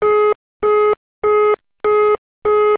busy.wav